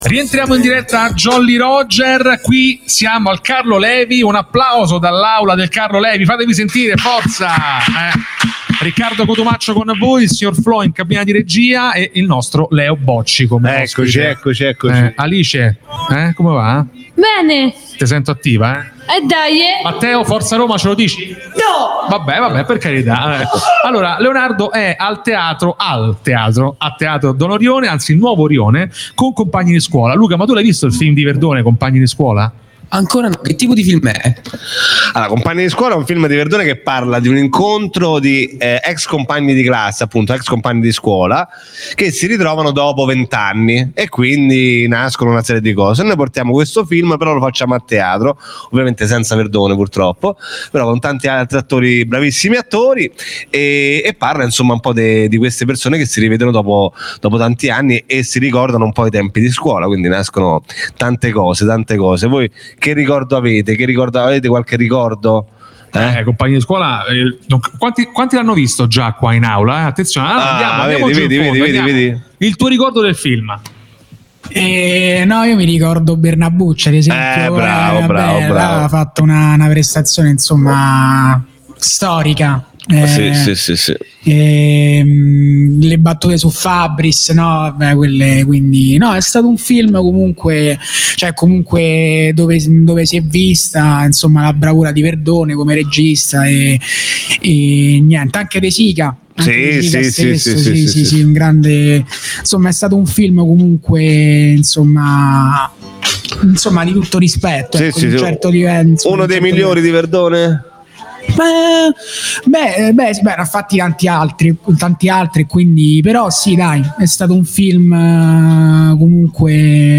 Jolly Roger - Puntata 16 - Intervista a Leonardo Bocci